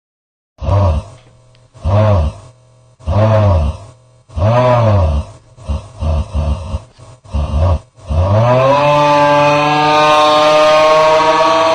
ohio ahh scream
ohio-ahh-scream.mp3